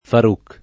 Faruk [ far 2 k ] (ar. Fārūq ) pers. m.